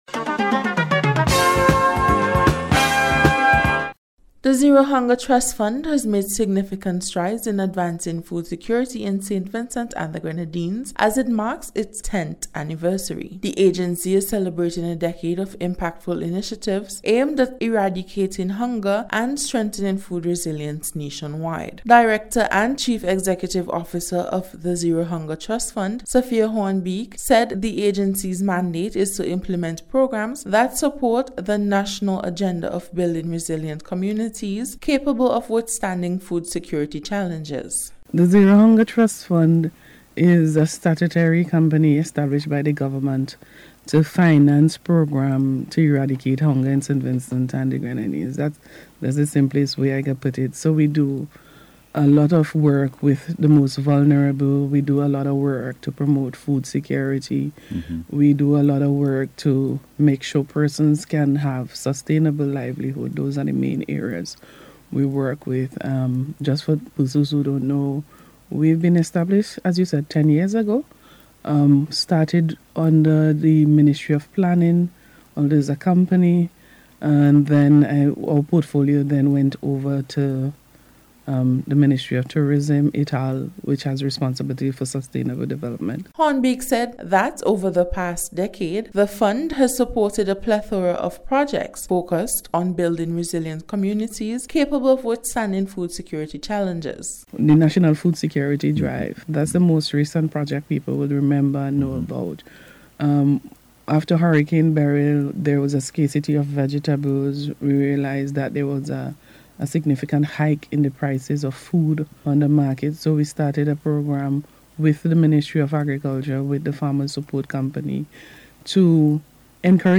ZERO-HUNGER-ANNIVERSARY-REPORT.mp3